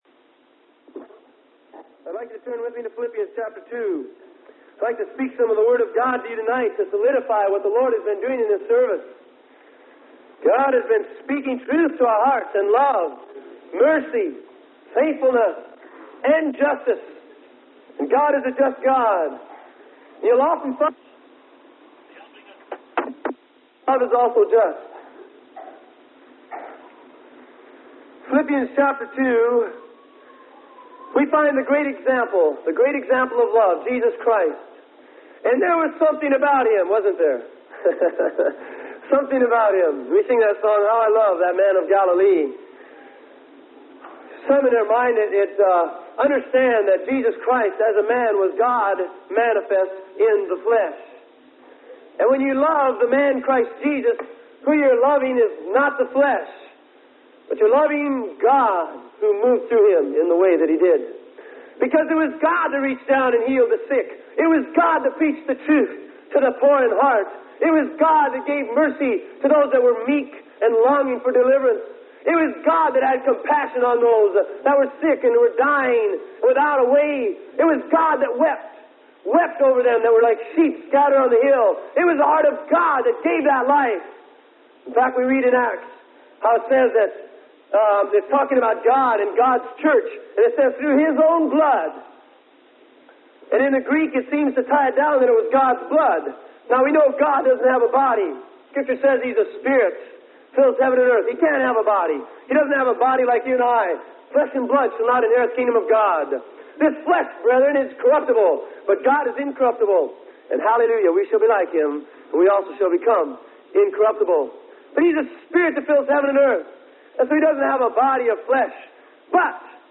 Sermon: Emptying Yourself.